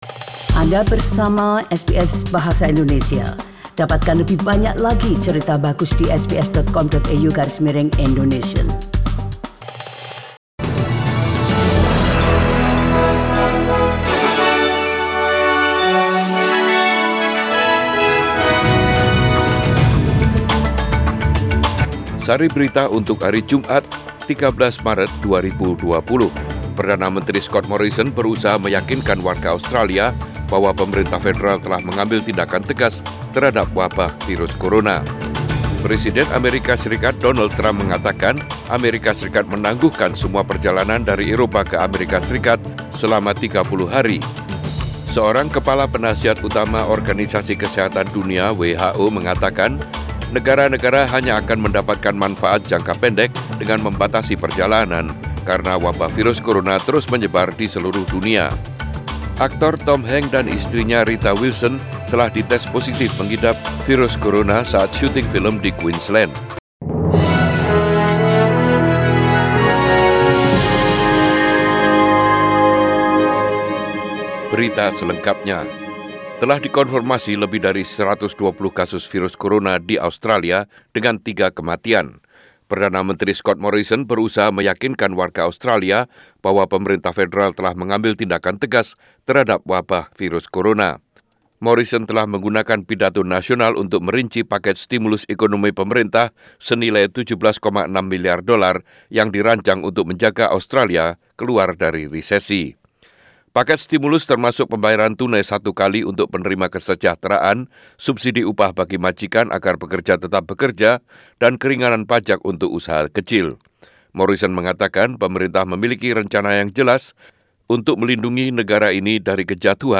SBS Radio News in Bahasa Indonesia - 13 March 2020